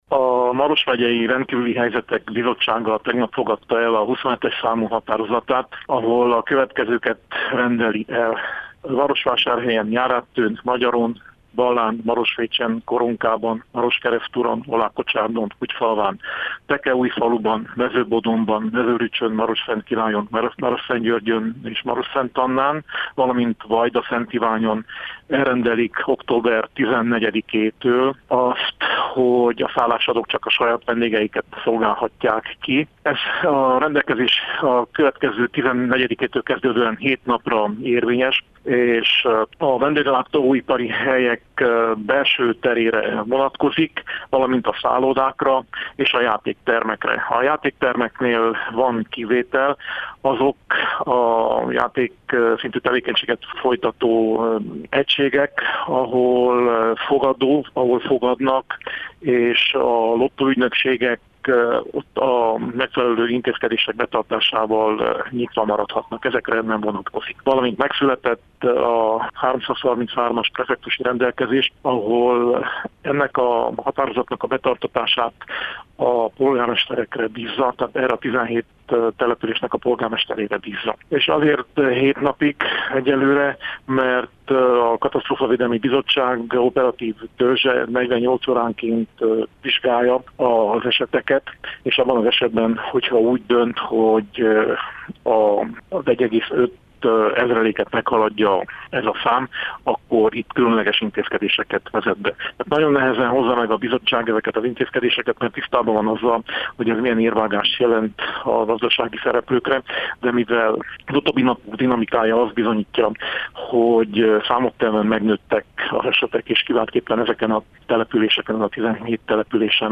Nagy Zsigmond Maros megyei alprefektus nyilatkozott rádiónknak.